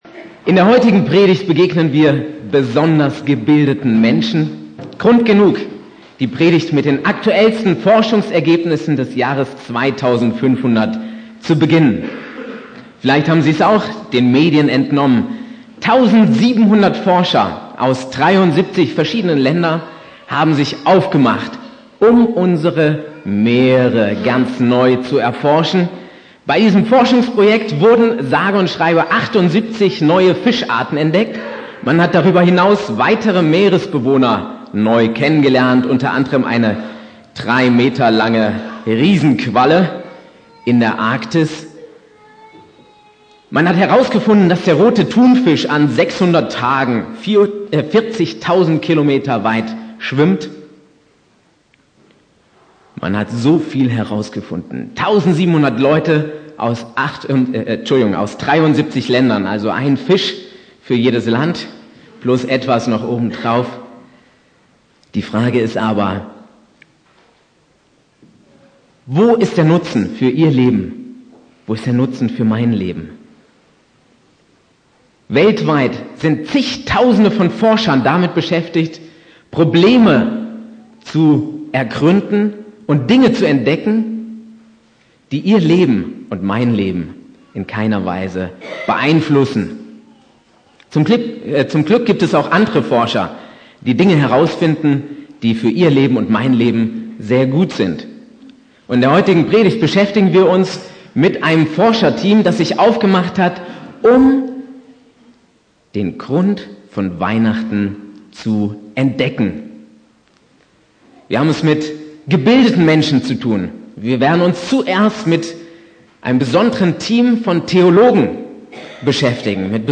Predigt
4.Advent Prediger